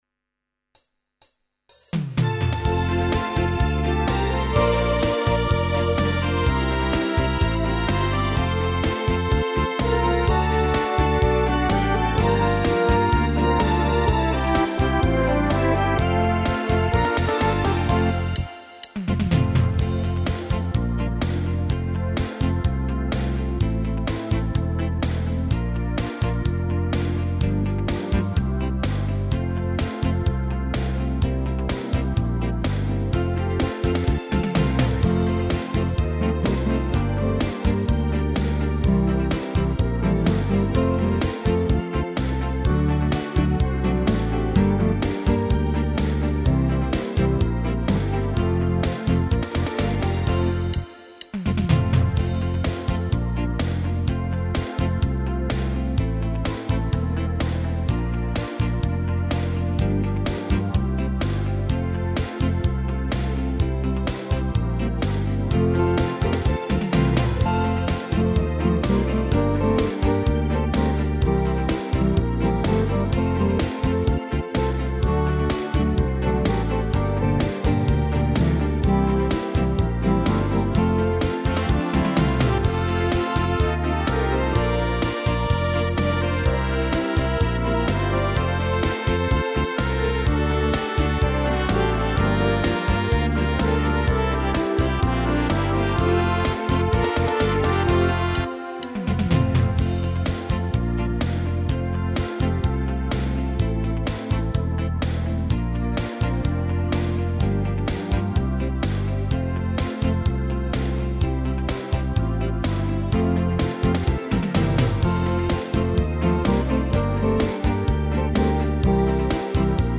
•   Beat  01.